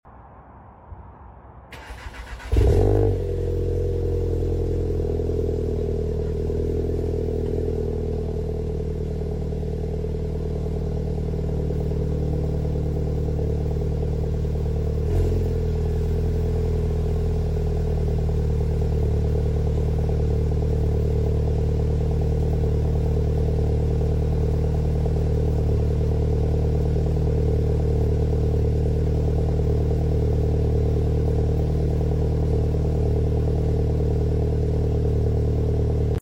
Volkswagen Golf 7.5 GTI cold sound effects free download
Volkswagen Golf 7.5 GTI cold start with resonator delete🔥